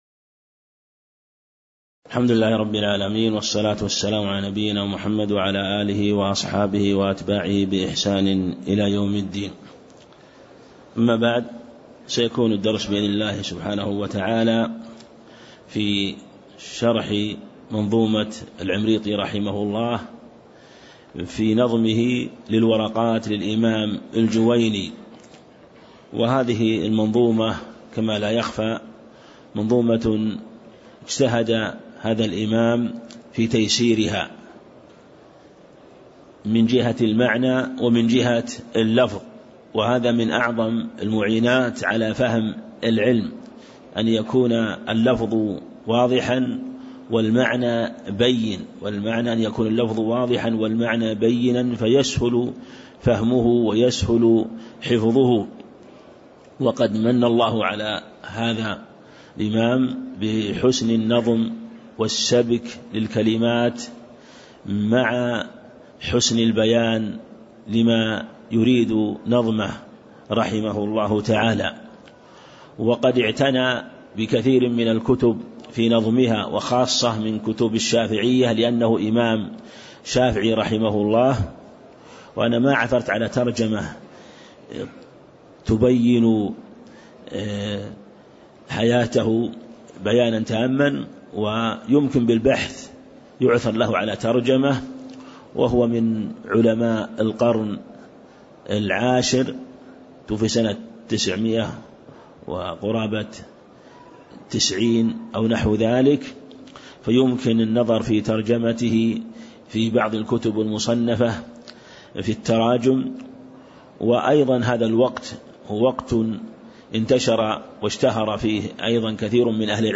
تاريخ النشر ١١ شوال ١٤٣٦ هـ المكان: المسجد النبوي الشيخ